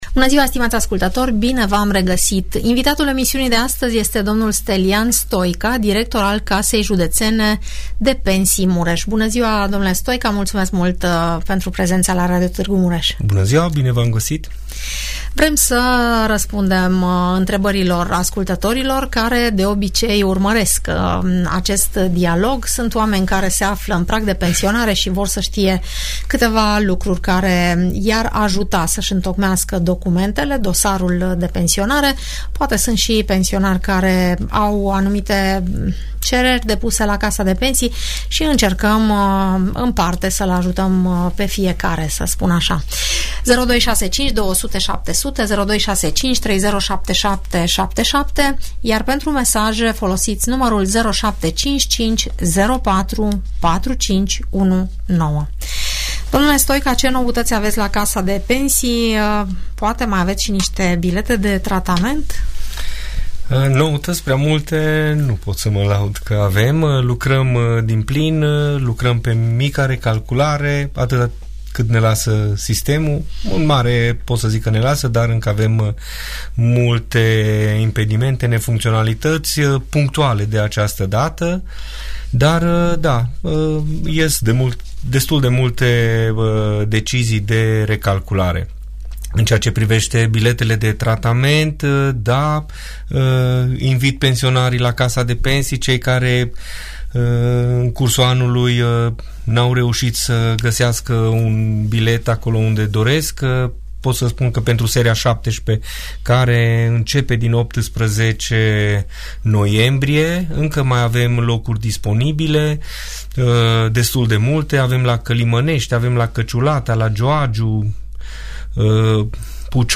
» Informații utile despre pensiile publice Informații utile despre pensiile publice Audiență radio cu întrebări și răspunsuri despre toate tipurile de pensii, în emisiunea "Părerea ta" de la Radio Tg Mureș.